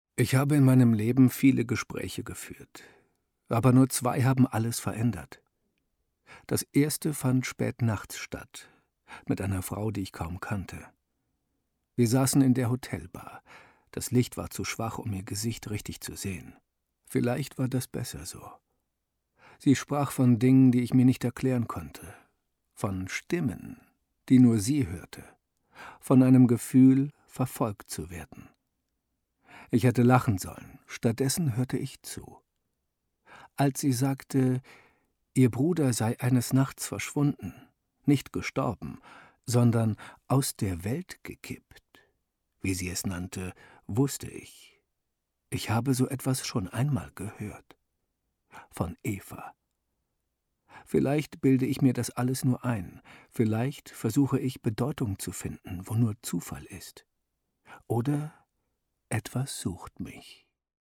hell, fein, zart, markant, sehr variabel
Erzählung
Audiobook (Hörbuch)